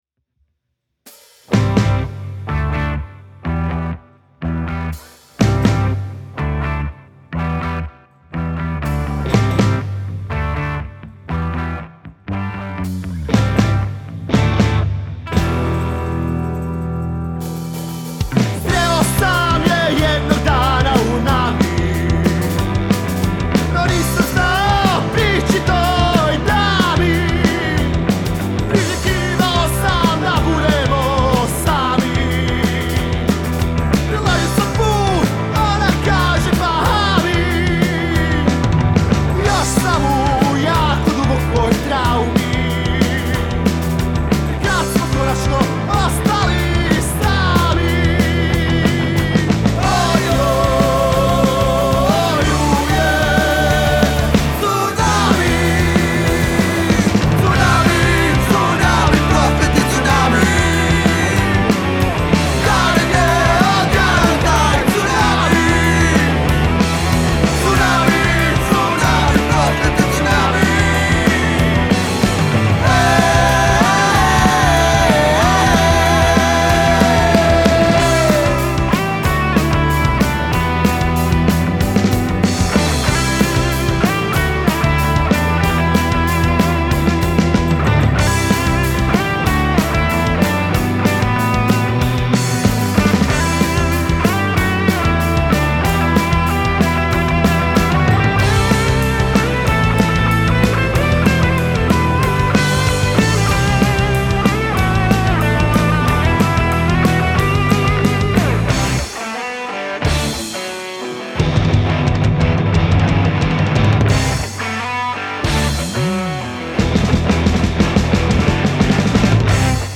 Punkerski power metal. Epic moćna i pjevna pjesmica